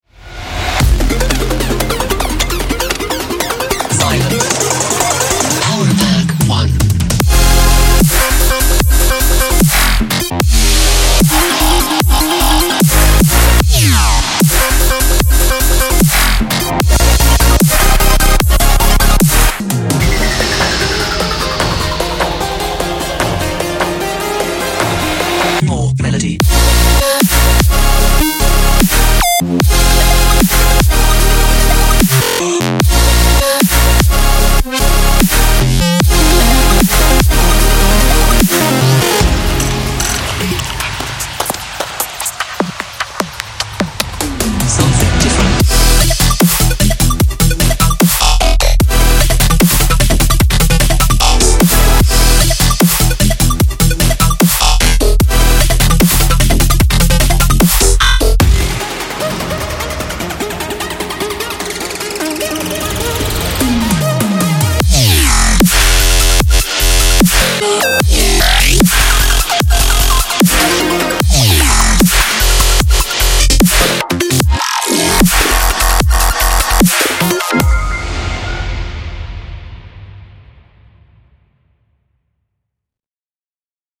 该软件包包含全方位的Supersaws,琶音填充，不断发展的科幻Pads和音色Synth-击,撞击和提升效
果，以及完整的重金属金属Basse,有力的Ki子， 军鼓和and, 包括完整循环。